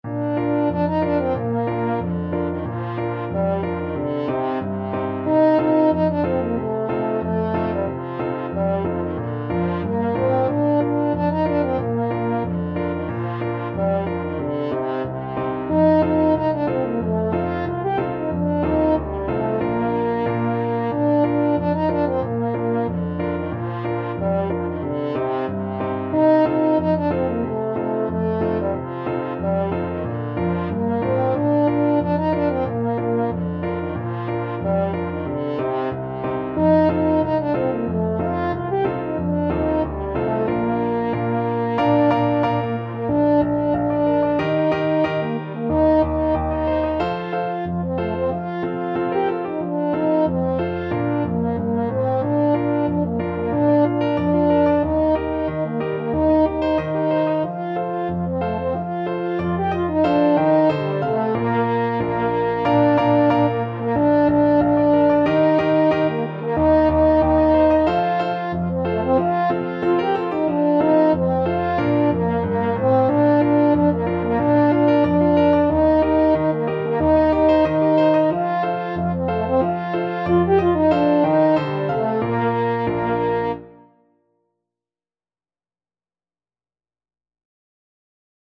French Horn
Traditional Music of unknown author.
2/4 (View more 2/4 Music)
Bb major (Sounding Pitch) F major (French Horn in F) (View more Bb major Music for French Horn )
Moderato =c.92
Danish